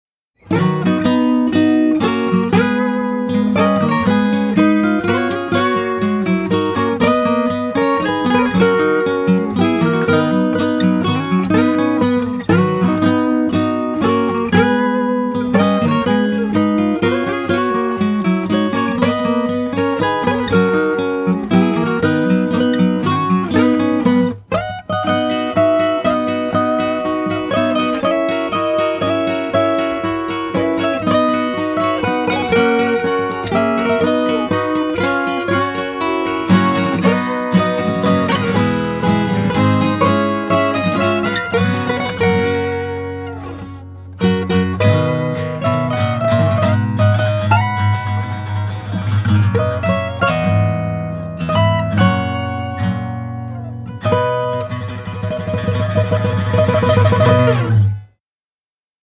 I put together a fun all-acoustic-guitar arrangement of “The Star-Spangled Banner” just for the balls of it (“balls” = “hell” or “fun”)!